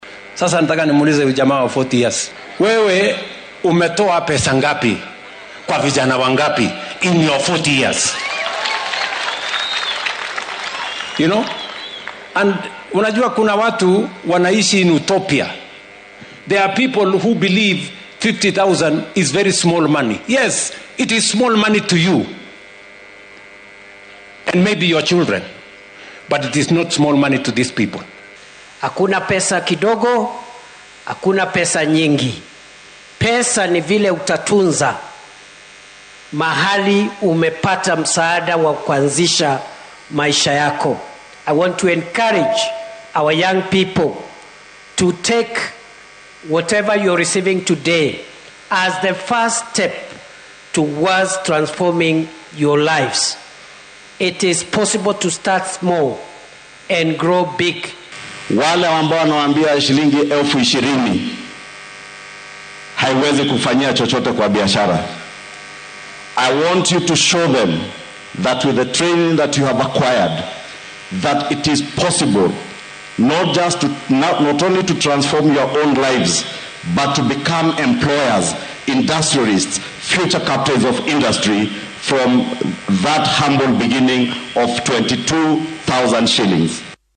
Ruto, oo guddoominayay barnaamijka Nyota ee dhalinyarada ka soo jeeda ismaamulada Murang’a, Kirinyaga, Nyeri iyo Nyandarua ee garoonka Kabiru-ini ee Nyeri, ayaa ka codsaday Kalonzo inuu u sharaxo dhalinyarada waxa uu u qabtay markii uu ahaa Madaxweyne ku xigeenka.